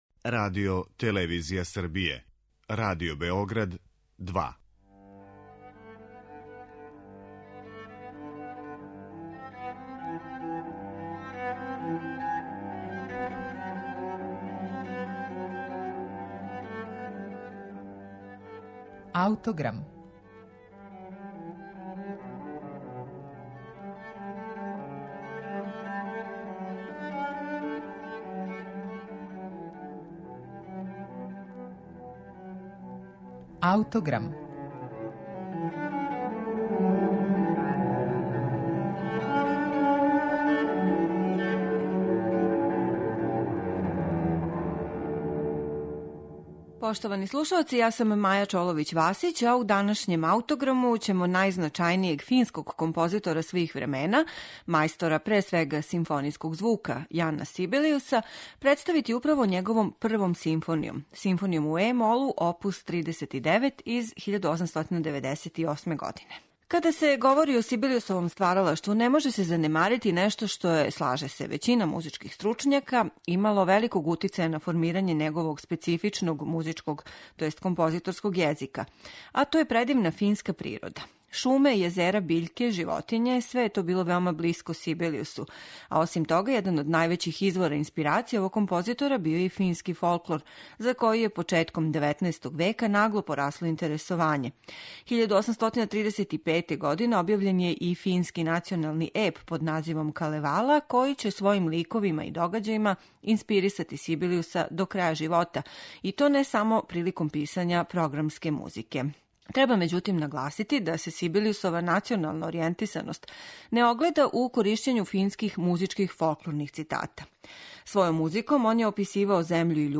Енглески композитор Арнолд Бакс компоновао је 1930. године дело под насловом Зимске легенде и дефинисао га као концертантну симфонију за клавир и оркестар.